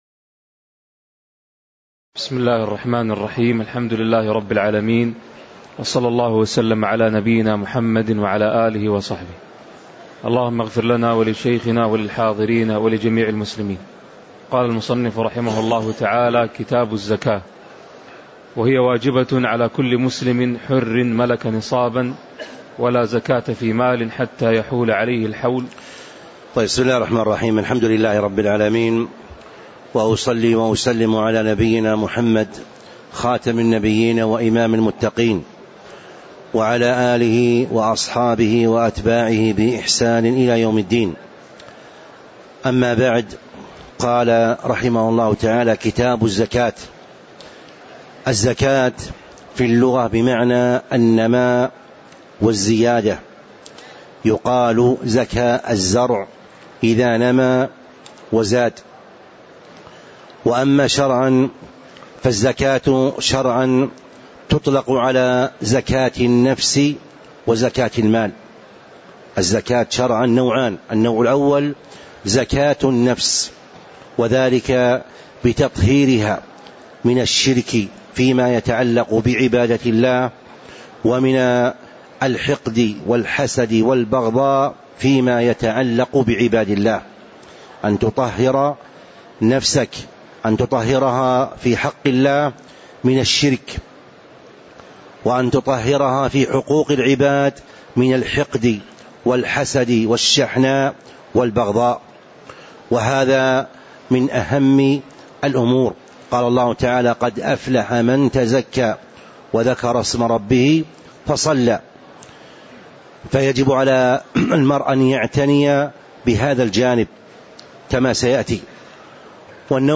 تاريخ النشر ٣٠ ذو الحجة ١٤٤٥ هـ المكان: المسجد النبوي الشيخ